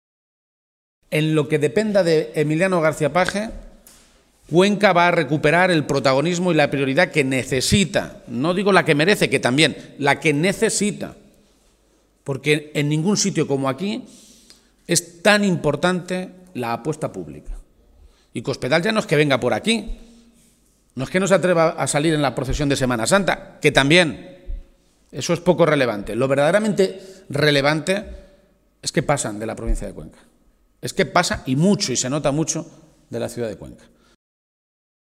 El líder de los socialistas castellano-manchegos hacía estas manifestaciones en la capital conquense, donde denunció además que “Cospedal se ceba particularmente con Cuenca” a través de su política de recortes en sanidad, educación y servicios sociales.